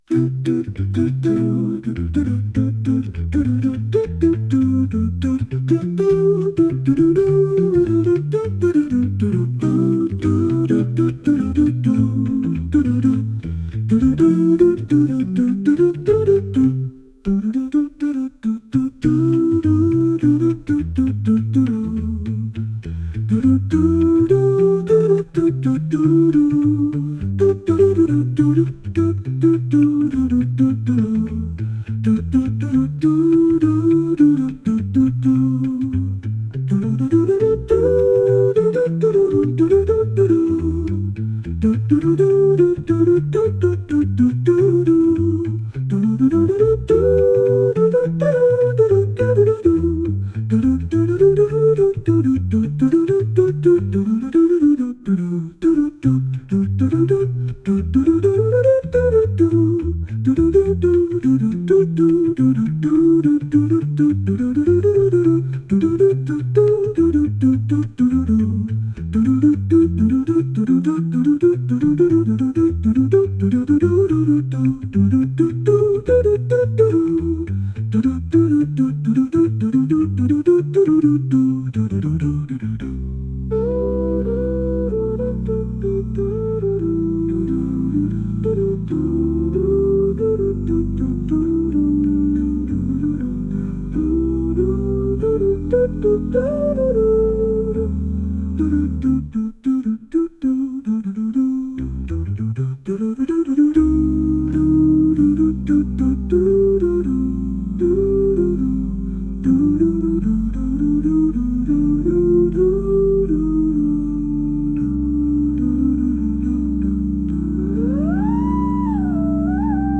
アカペラを含んだボサノバ曲です。